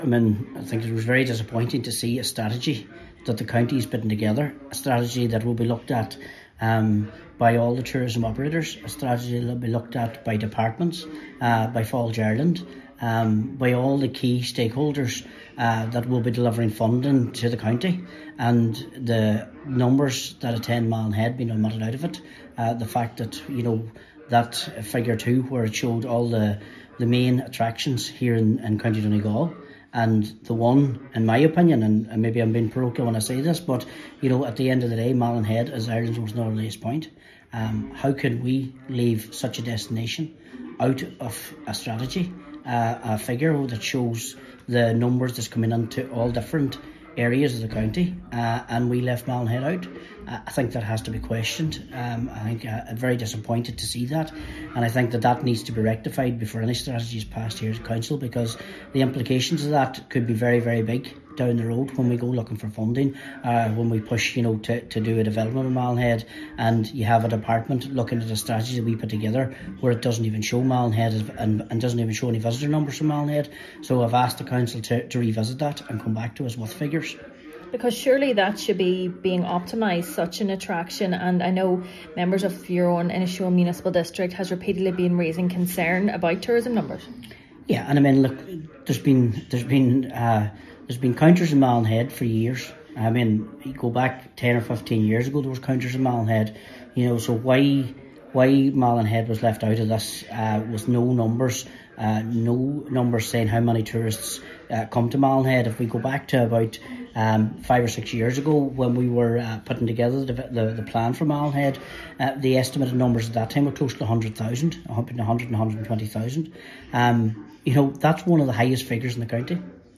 Councillor Martin McDermott says this raises serious questions over the consideration being given to Inishowen in the strategy: